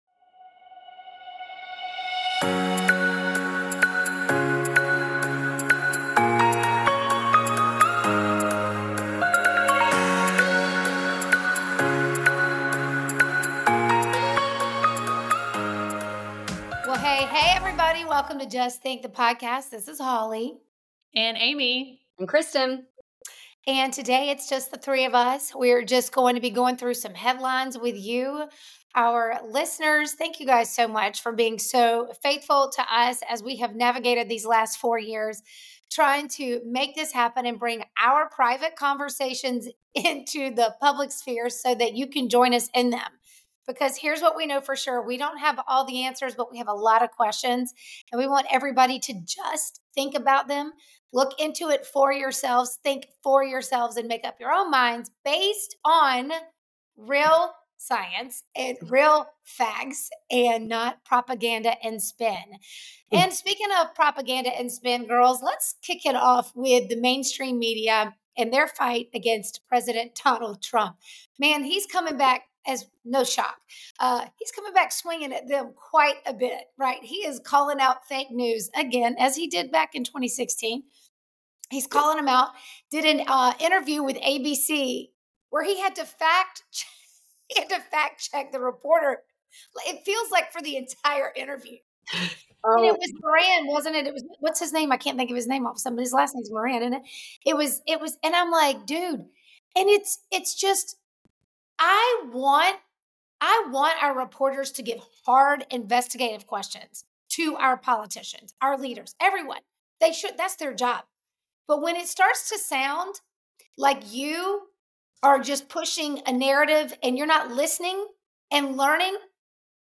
Just Think: The Podcast is hosted by 3 American women who invite you into their conversation about current events and challenge you to think critically about the information you are given.